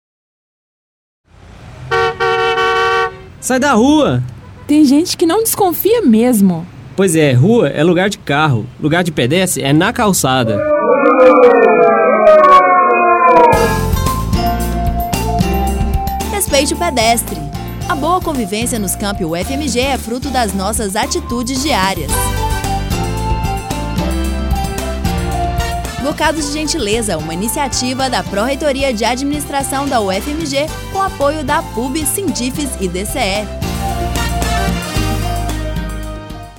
Para divulgar a iniciativa, o Núcleo de Publicidade da emissora produziu uma série de quatro spots, abordando os temas lixos, áreas verdes e trânsito. Situações de mau exemplo – infelizmente, tão corriqueiras no Campus – são seguidas por muitas vaias.